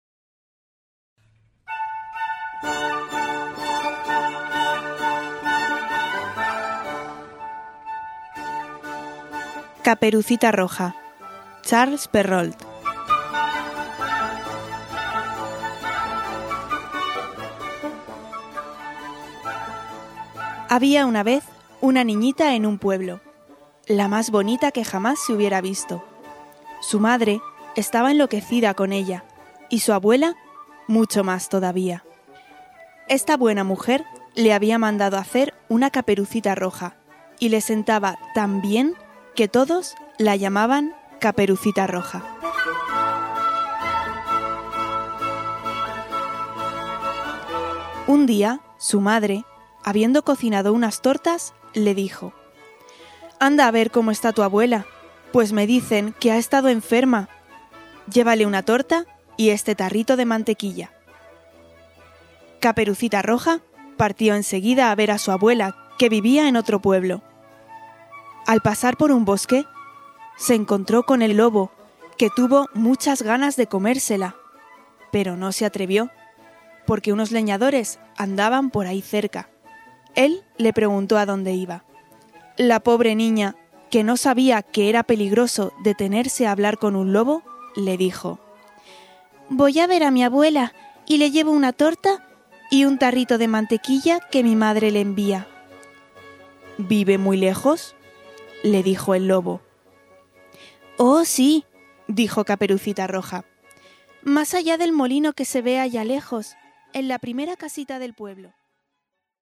Música: MusOpen